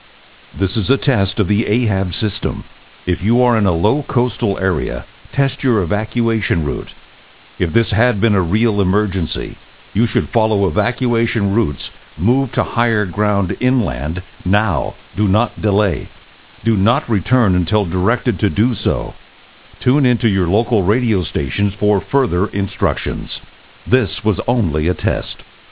Evacuation Route Message